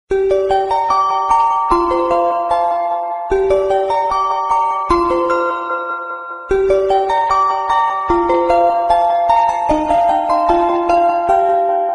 Thể loại nhạc chuông: Nhạc tin nhắn